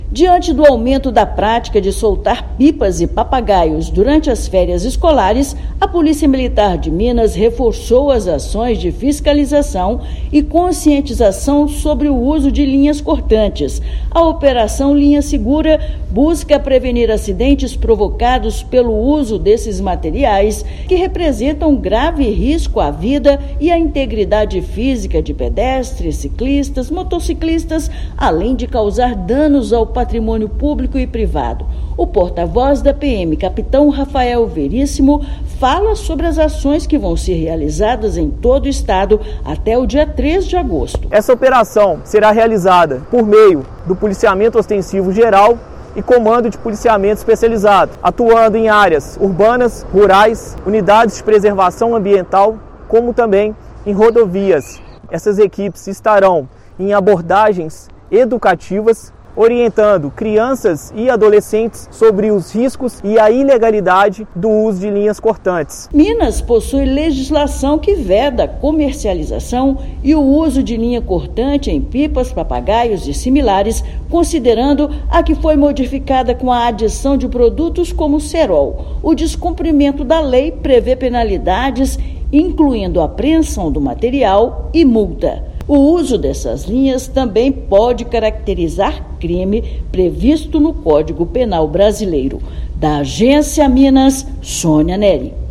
Corporação reforçará efetivo na conscientização e fiscalização de quem solta papagaios, pipas e similares durante período de férias. Ouça matéria de rádio.